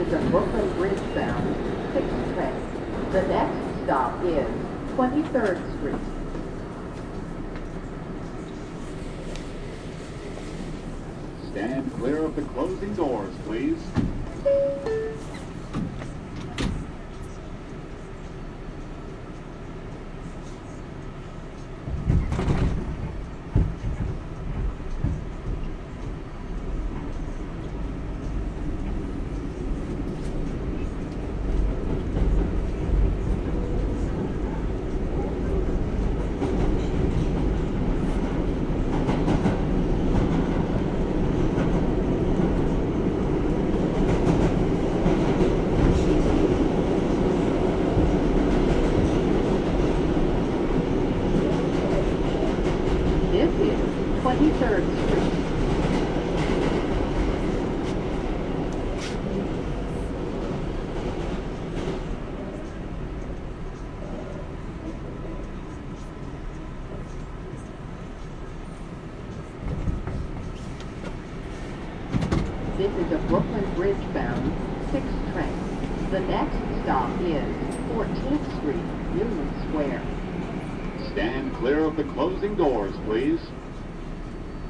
6 train, Manhattan